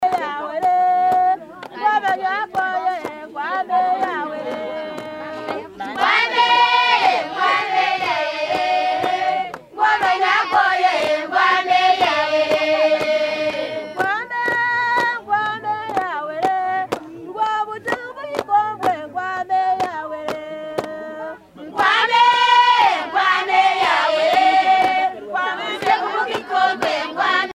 Mémoires et Patrimoines vivants - RaddO est une base de données d'archives iconographiques et sonores.
Musique de divertissement